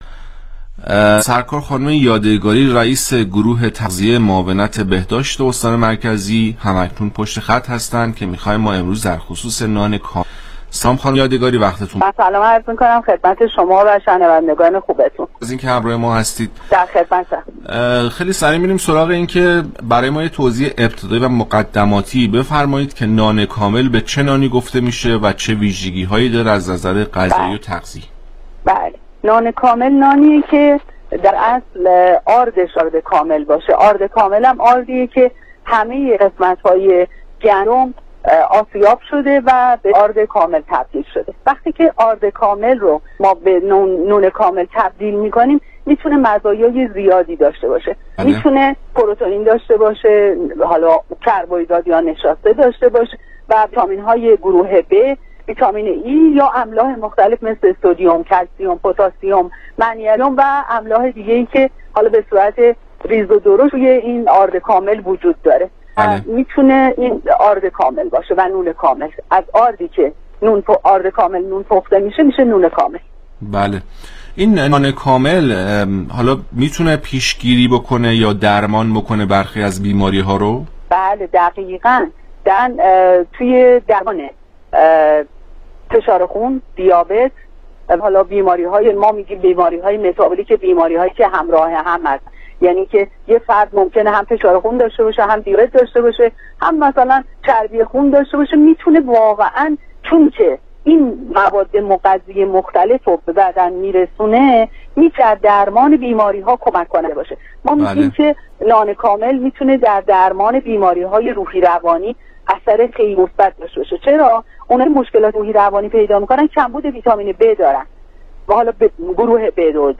گفتگوی تلفنی برنامه رادیویی پاسخ